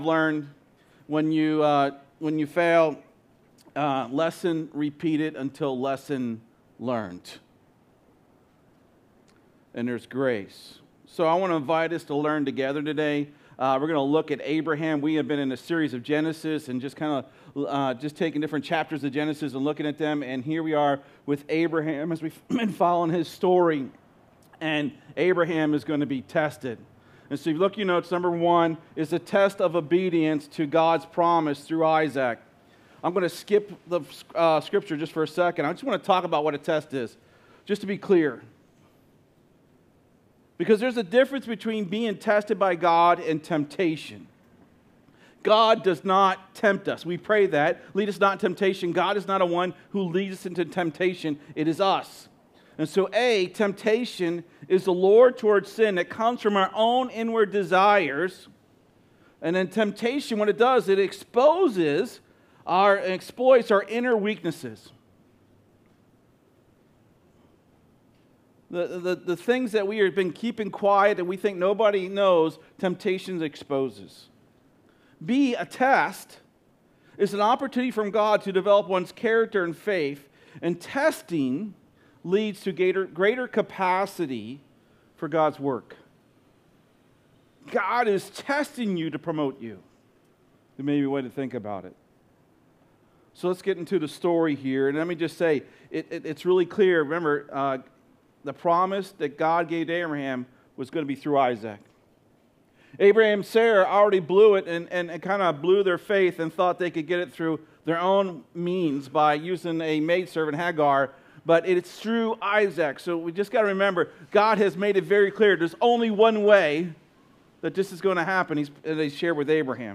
SERMON DESCRIPTION God tested Abraham’s faith and obedience by asking him to sacrifice his son Isaac, to which Abraham responded with immediate trust in God's provision.